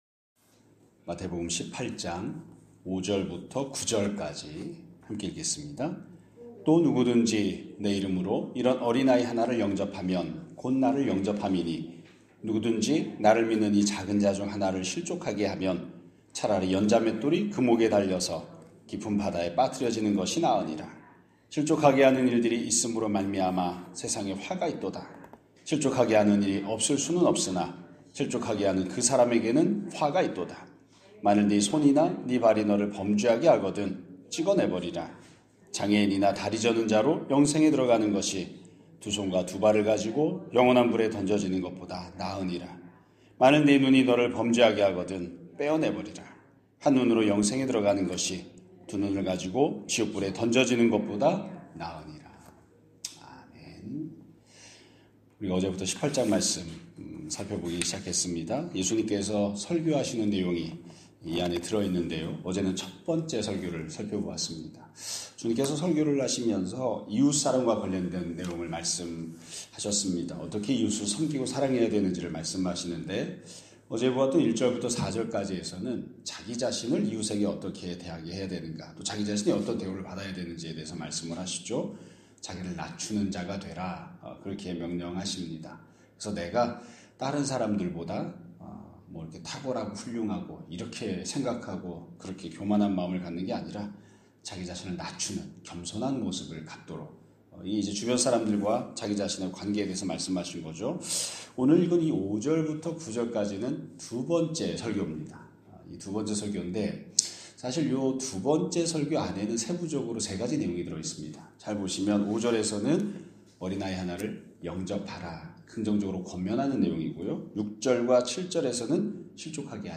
2025년 12월 4일 (목요일) <아침예배> 설교입니다.